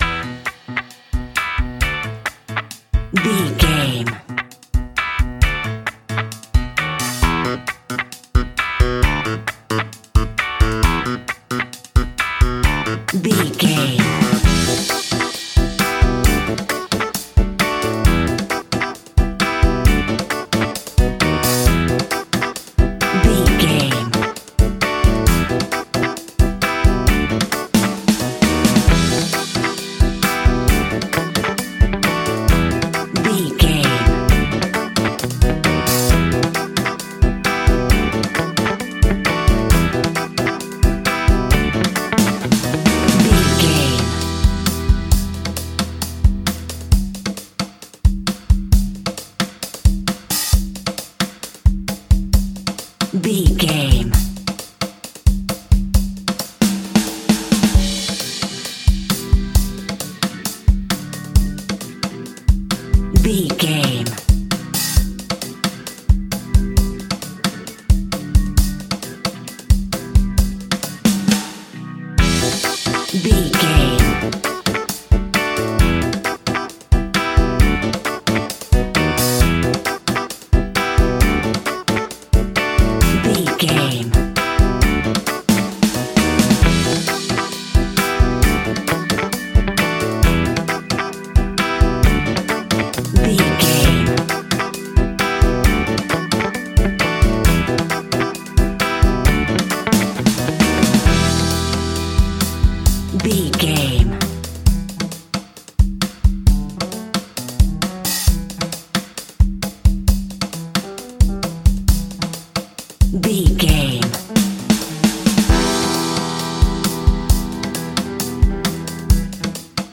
A groovy piece of upbeat Ska Reggae!
Aeolian/Minor
Fast
dub
laid back
chilled
off beat
drums
skank guitar
hammond organ
percussion
horns